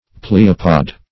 pleopod - definition of pleopod - synonyms, pronunciation, spelling from Free Dictionary
Pleopod \Ple"o*pod\, n.; pl. E. Pleopods, L. Pleopoda. [Gr.